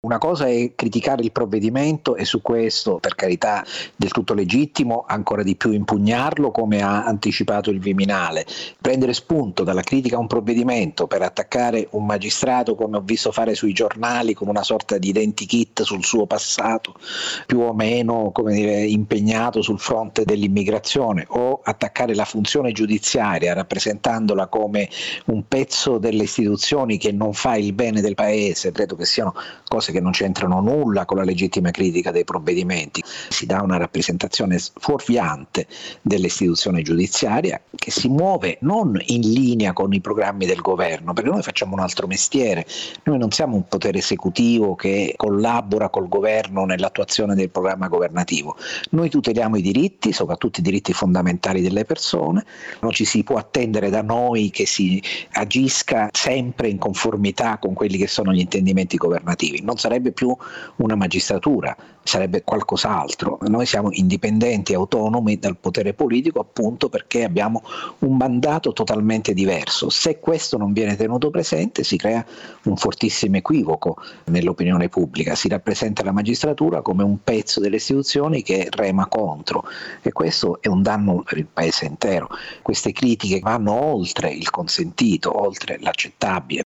Giuseppe Santalucia è presidente dell’Associazione Nazionale Magistrati: